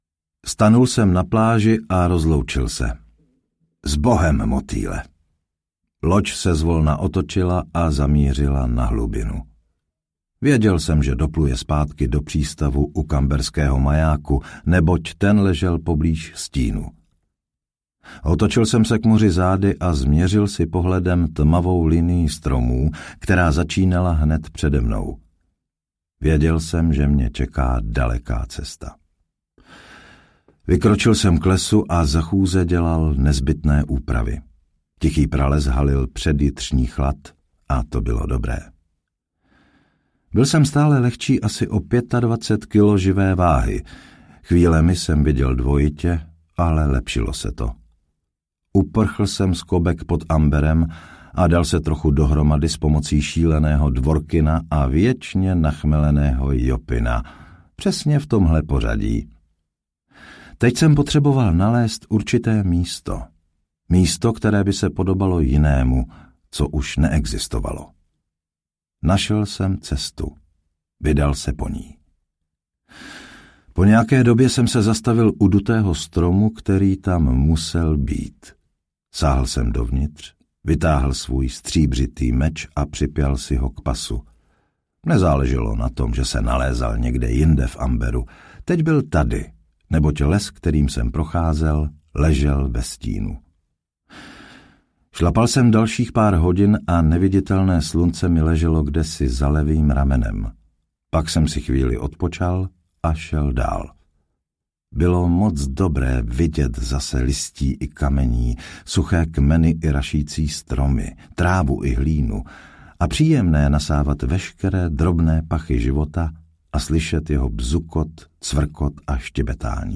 Amber 2 - Pušky Avalonu audiokniha
Ukázka z knihy